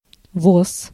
Ääntäminen
Ääntäminen US RP : IPA : /kɑːt/ GenAm: IPA : /kɑɹt/